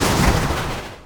▶     Move sound replaces Slardar's default movement sound for the duration.
Sprint_move.mp3